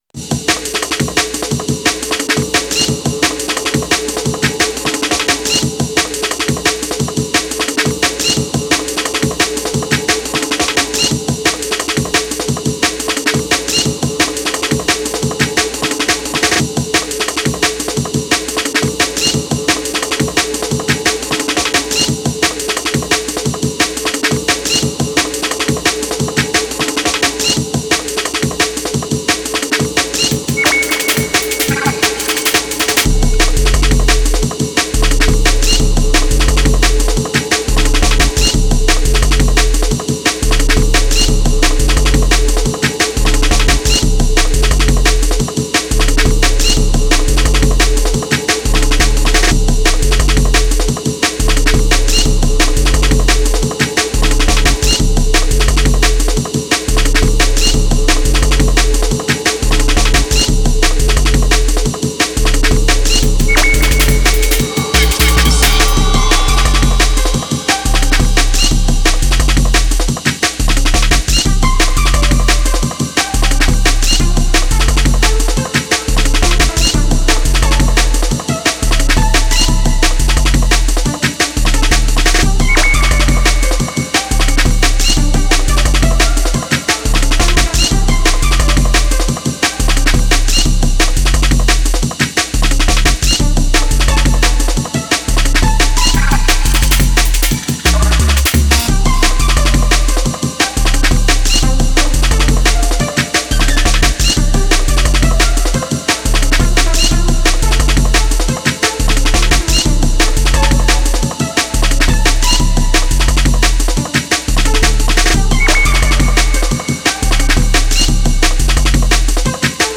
Style: Drum & Bass